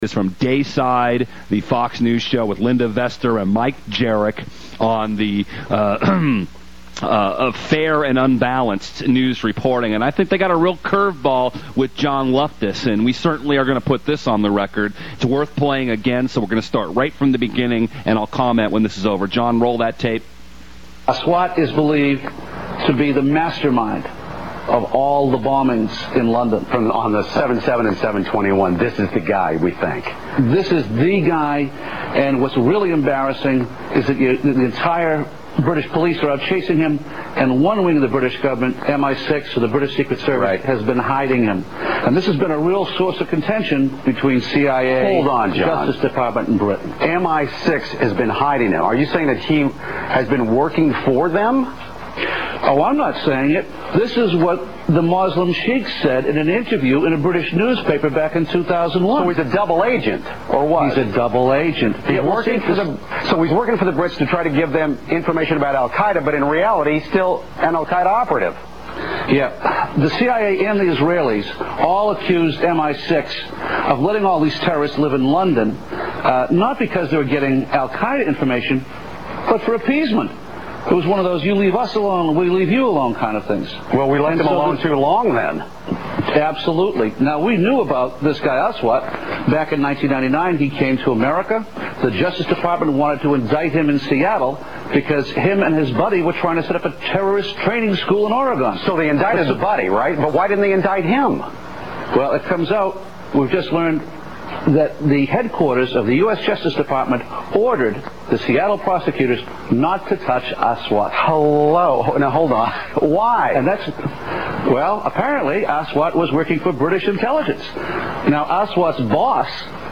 FOX News interviews John Loftus on the 7/07 Londong bombings